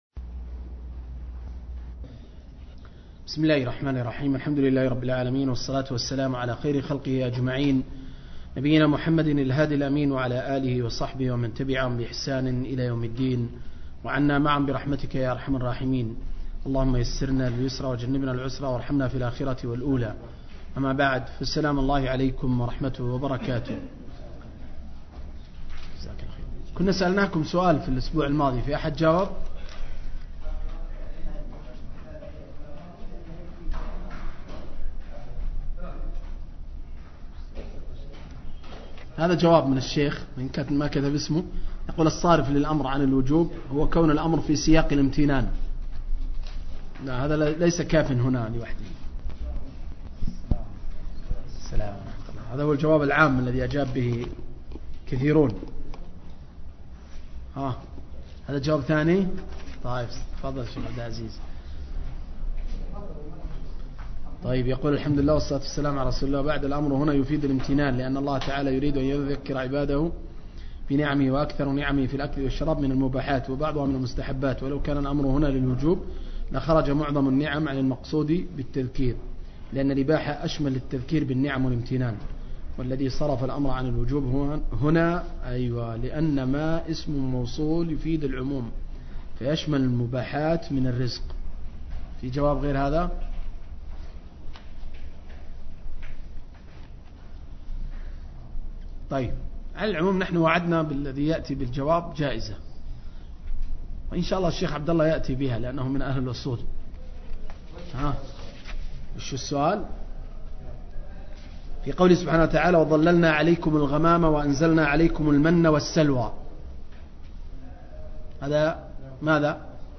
017- عمدة التفسير عن الحافظ ابن كثير – قراءة وتعليق – تفسير سورة البقرة (الآيات 58-61)